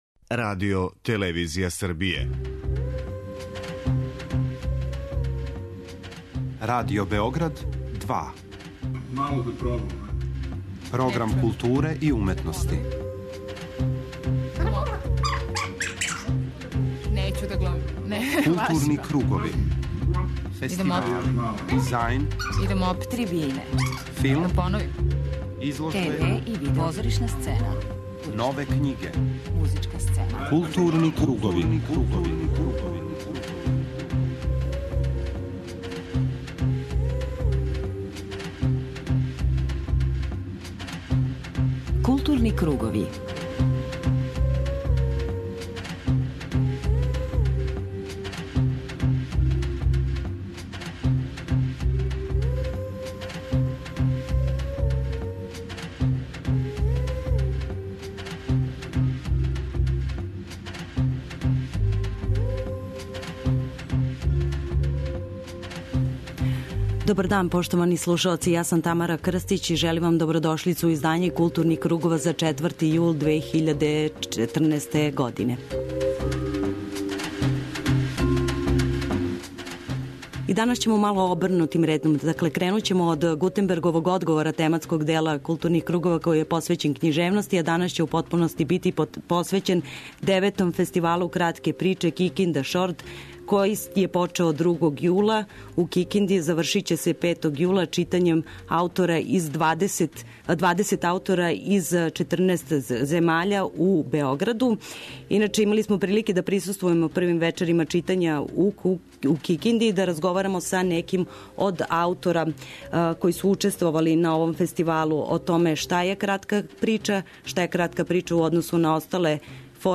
О томе шта је кратка прича и о значају овог фестивала, разговараћемо са писцима учесницима.